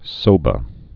(sōbə)